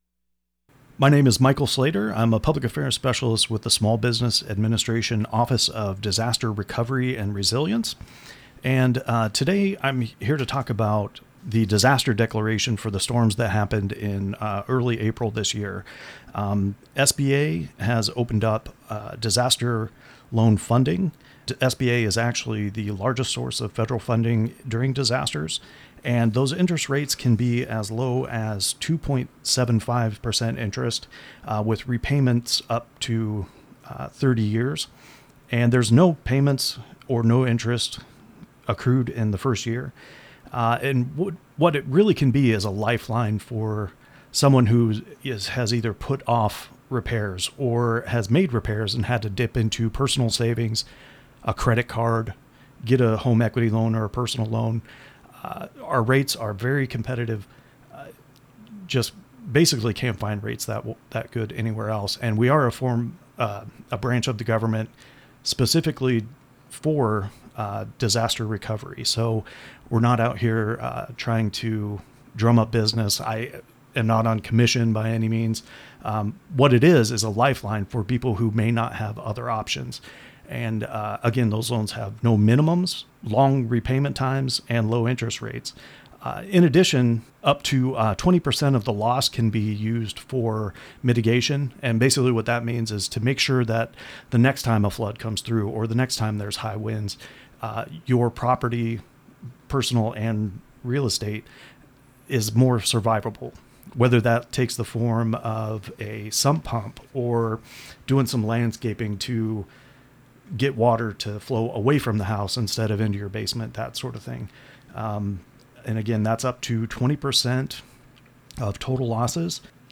In addition to this talk, more information on this process will be included below.